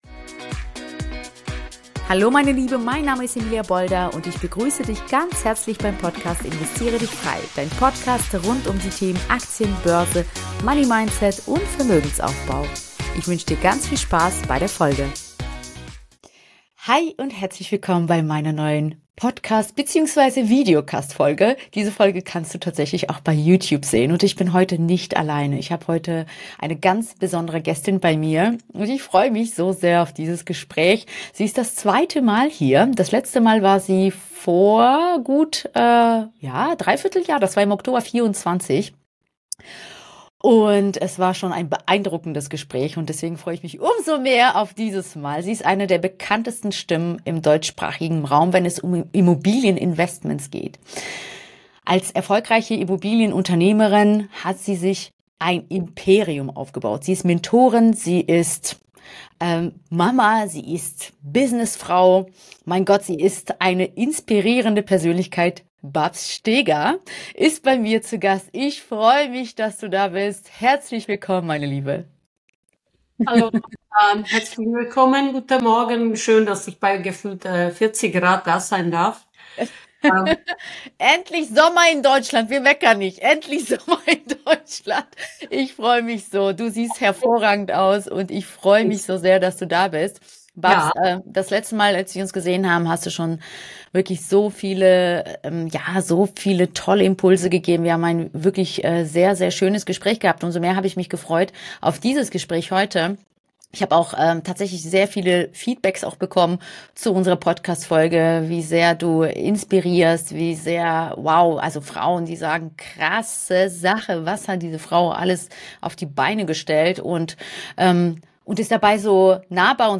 Eine starke Fortsetzung unseres ersten Gesprächs – unbedingt reinhören!